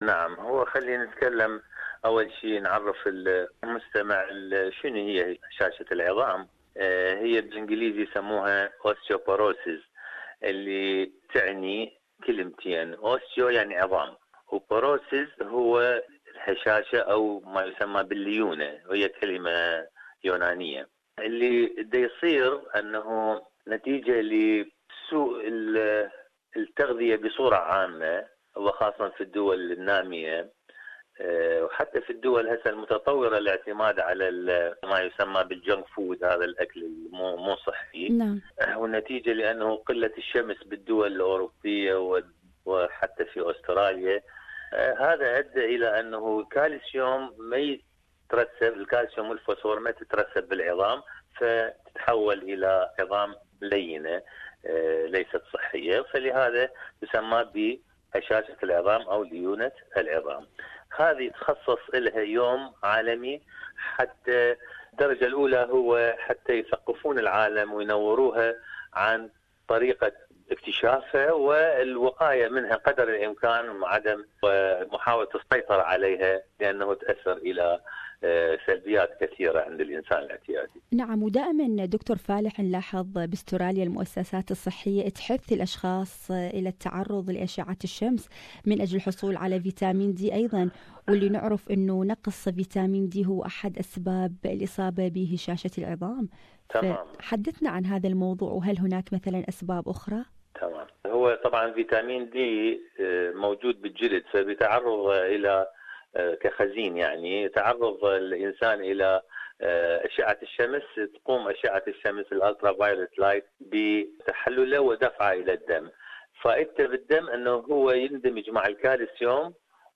World Osteoporosis Day (WOD), marked on October 20 each year, is a year-long campaign dedicated to raising global awareness of the prevention, diagnosis and treatment of osteoporosis and related bone diseases. It aims to put osteoporosis and fracture prevention on the global health agenda and reaches out to health-care professionals, the media, policy makers and the public at large. More on this issue, listen to this interview